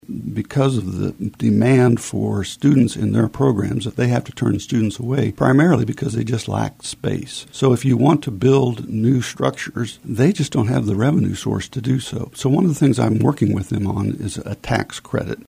Tom Phillips, a Kansas House of Representative member representing the 67th district, appeared on today’s episode of In Focus to preview some of what he will be working on in the upcoming legislative session in the areas of healthcare, higher education and “dark store theory.”